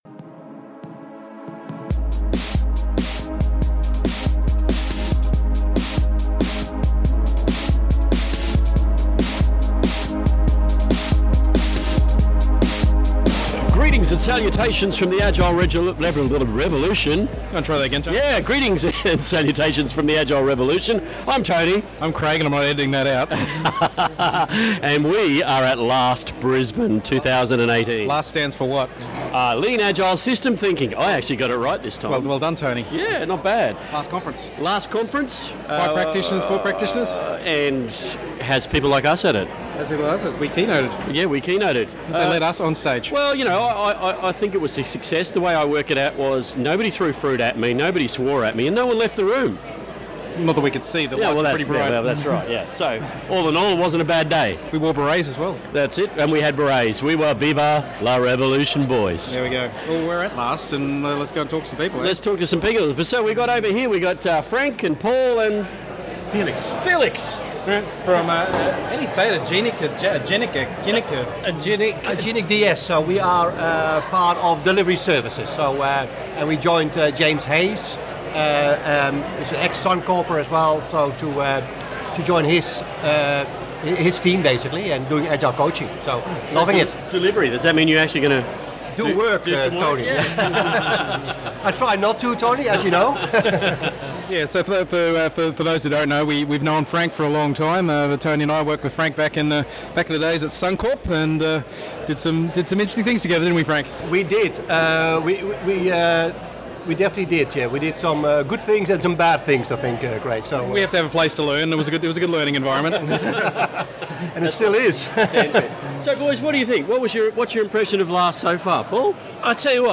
Episode 156: LAST 2018 Brisbane Vox Pop